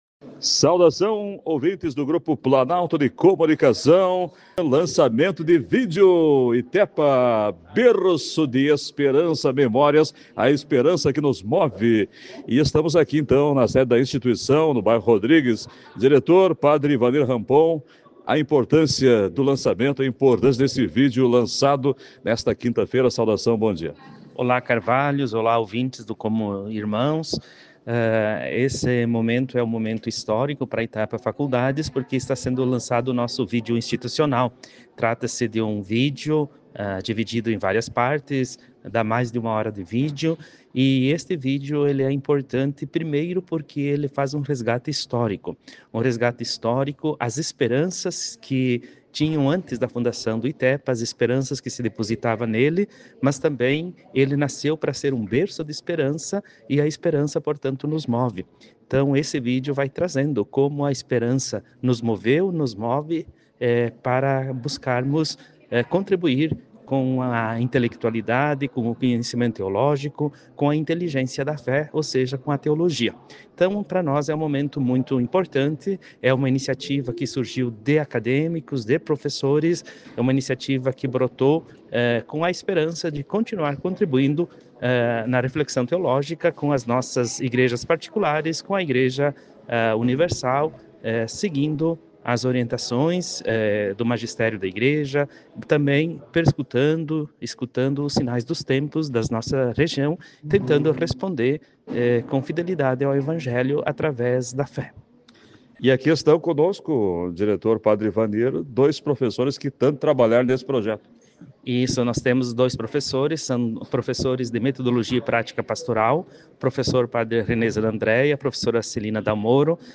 ENTREVISTA-NA-ITEPA-FACULDADES.mp3